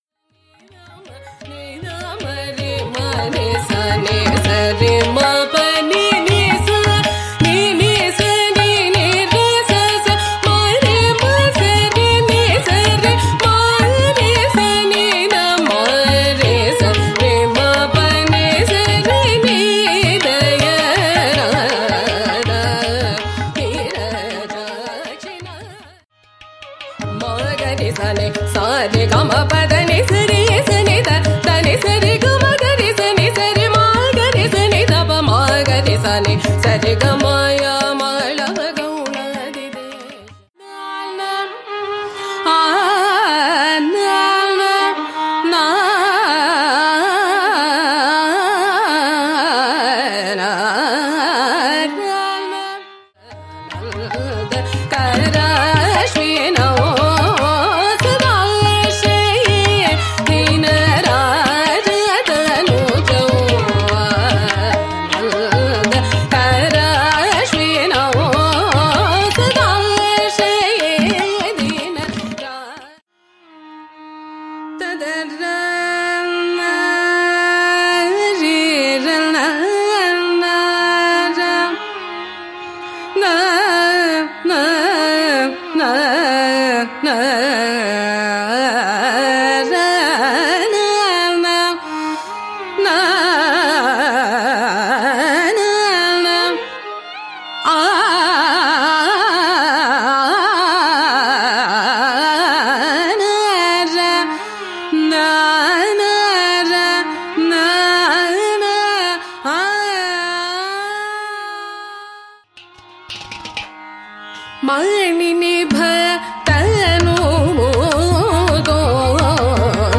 Concert Excerpt